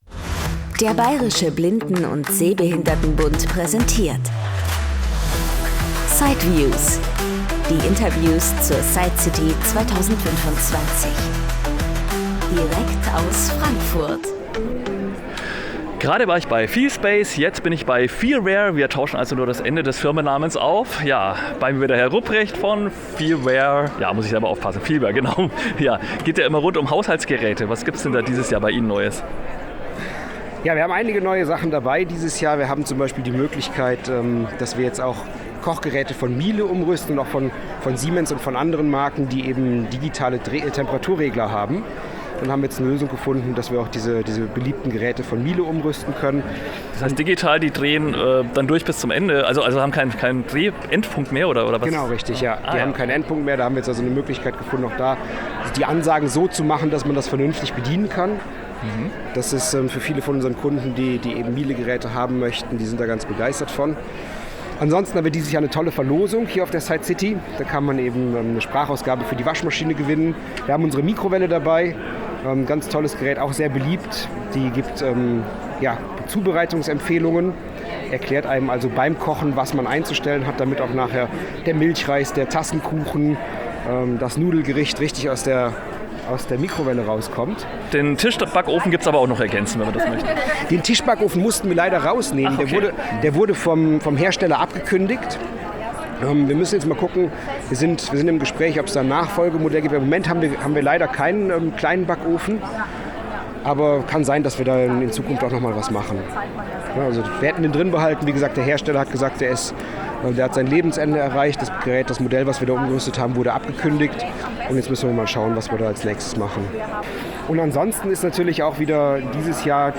Kern des Podcasts sind Interviews und Berichte von der SightCity in Frankfurt, der weltweit größten Messe für Blinden- und Sehbehindertenhilfsmittel.